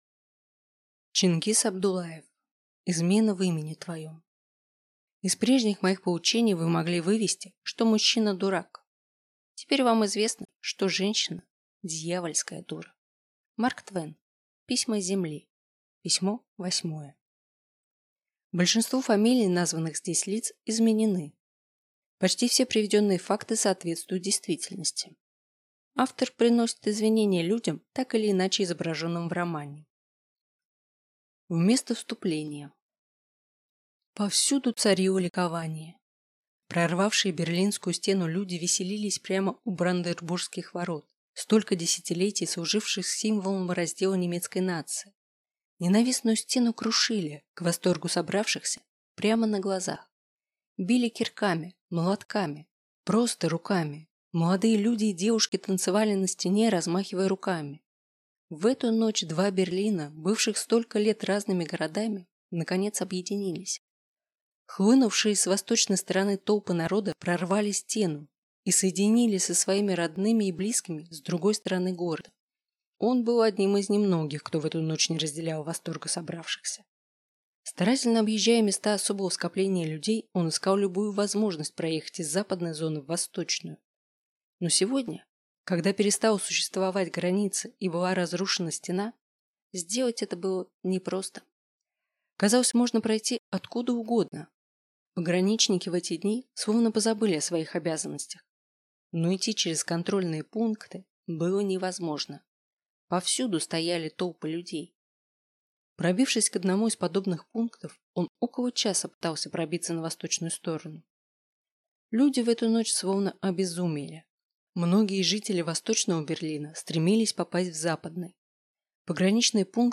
Аудиокнига Измена в имени твоем | Библиотека аудиокниг
Прослушать и бесплатно скачать фрагмент аудиокниги